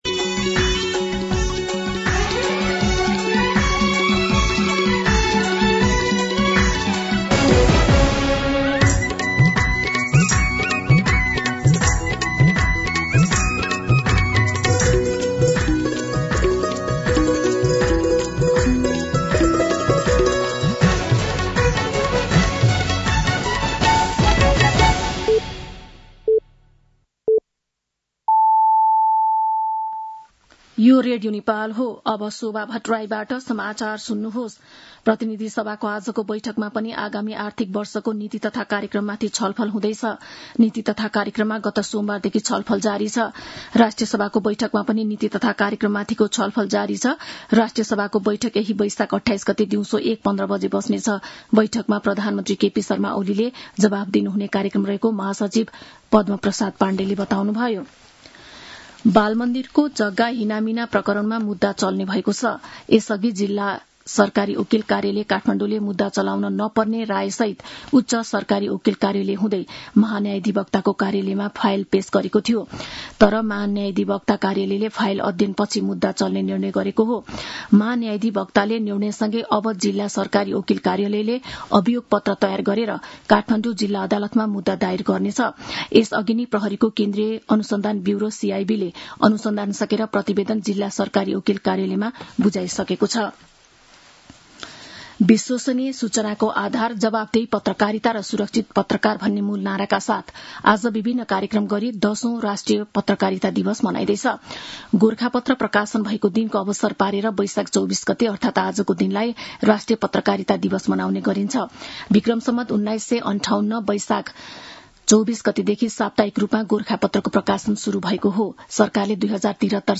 मध्यान्ह १२ बजेको नेपाली समाचार : २४ वैशाख , २०८२